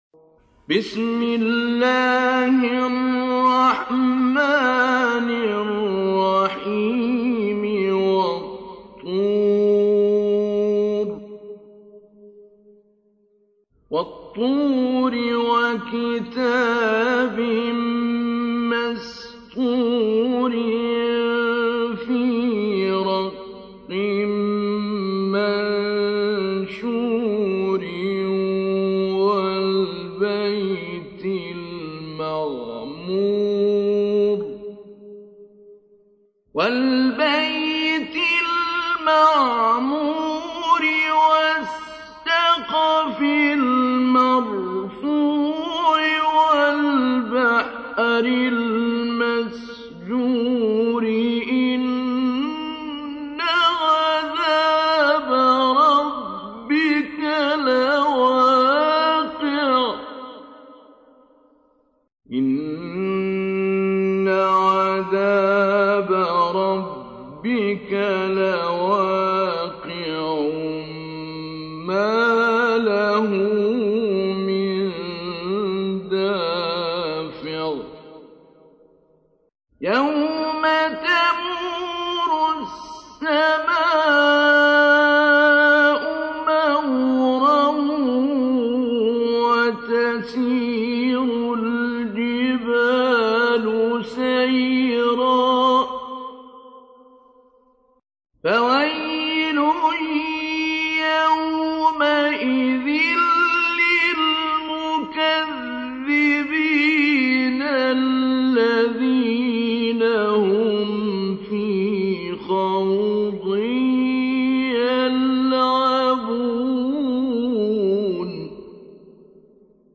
تحميل : 52. سورة الطور / القارئ محمد صديق المنشاوي / القرآن الكريم / موقع يا حسين